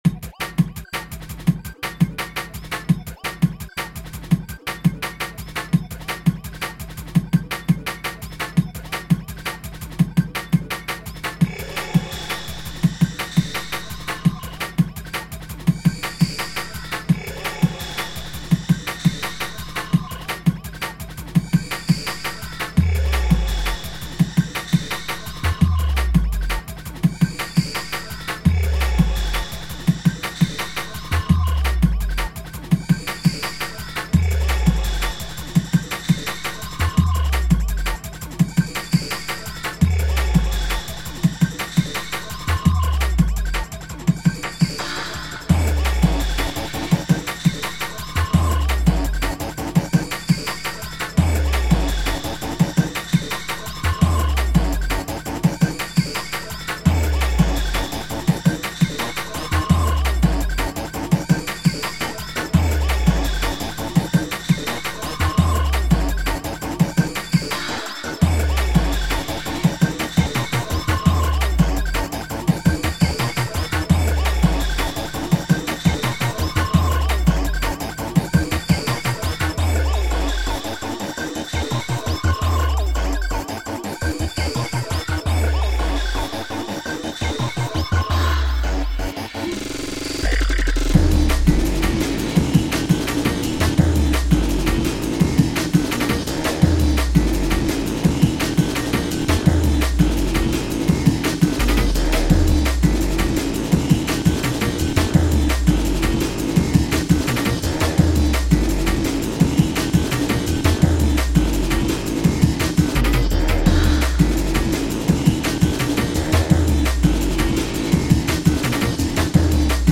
dance/electronic
Drum & bass